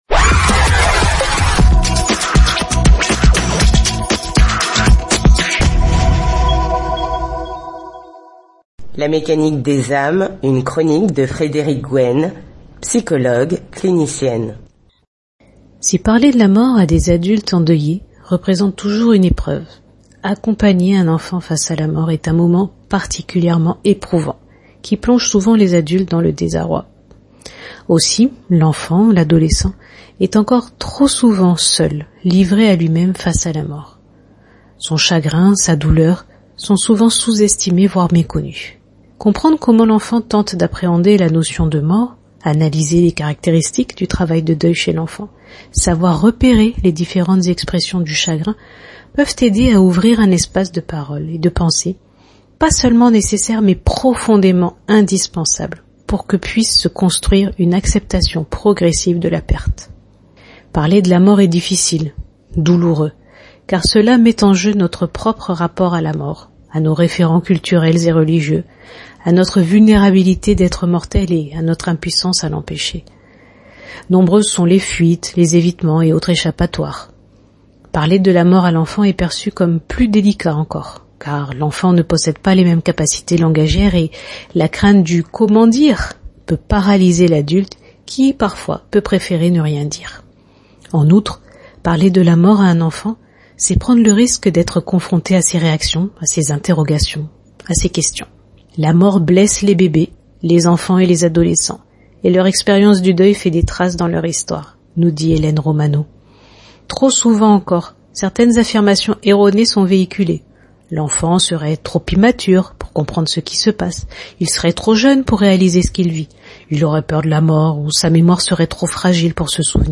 Un programme de Radio Mayouri Campus La radio du savoir à retrouver du lundi au vendredi à 6h40, 7h40, 12h40, 18h40 et 20h40.